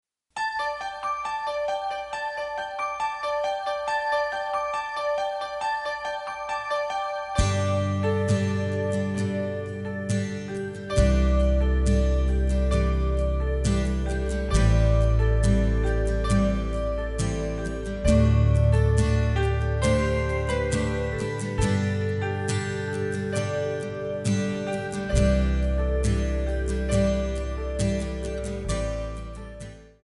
Backing track files: 1980s (763)